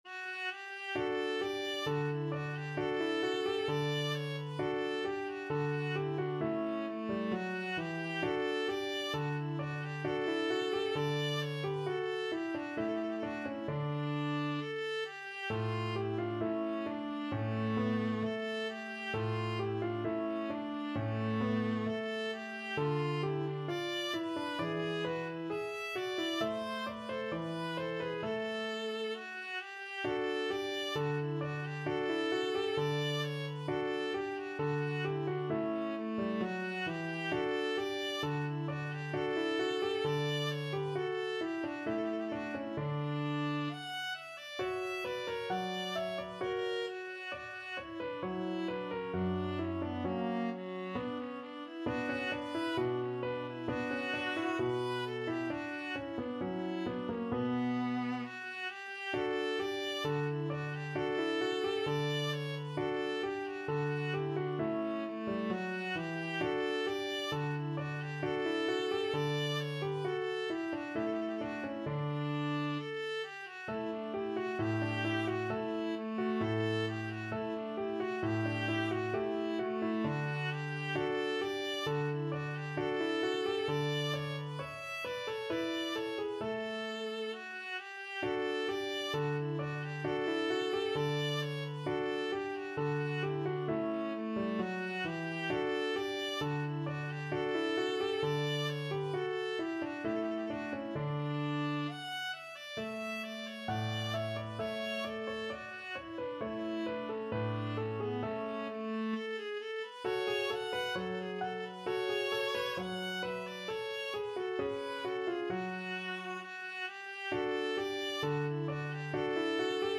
Viola
2/2 (View more 2/2 Music)
F#4-F#6
D major (Sounding Pitch) (View more D major Music for Viola )
~ = 100 Allegretto =c.66
Classical (View more Classical Viola Music)